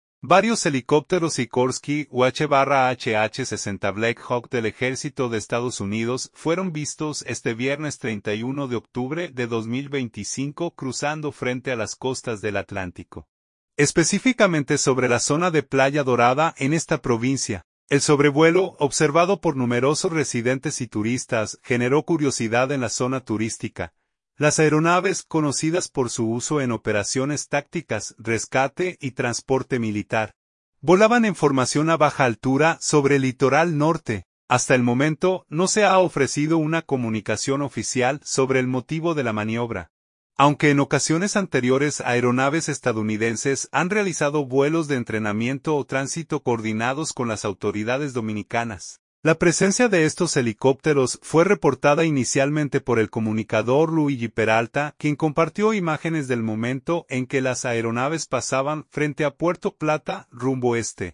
Avistados helicópteros Black Hawk del Ejército de EE. UU. sobrevolando la costa de Puerto Plata
Las aeronaves, conocidas por su uso en operaciones tácticas, rescate y transporte militar, volaban en formación a baja altura sobre el litoral norte.